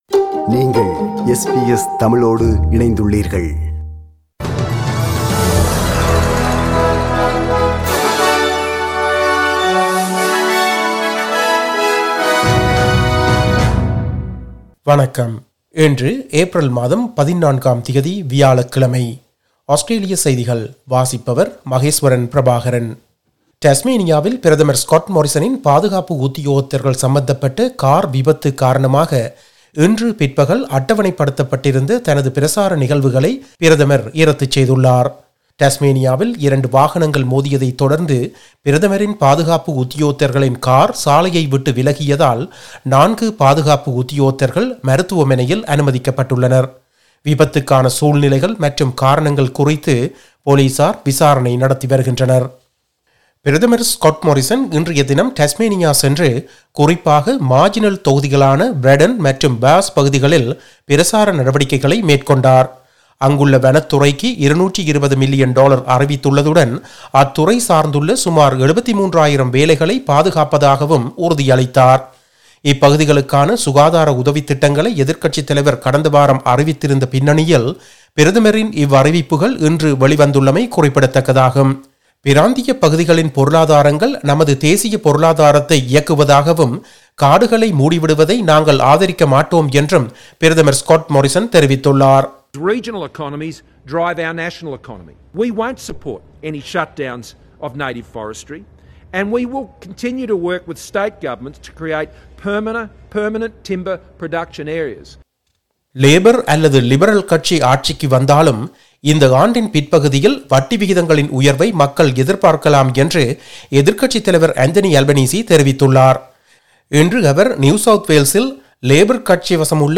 Australian news bulletin for Thursday 14 April 2022.